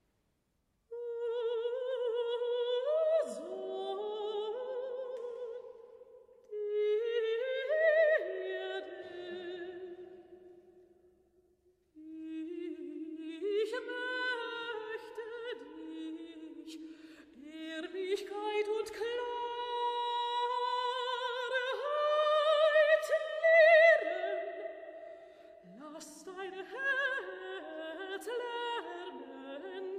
Neue Musik
Vokalmusik
Solostimme(n)
Sopran (1)